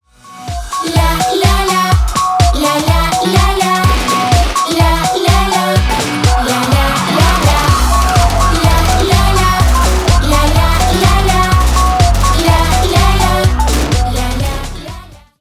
весёлые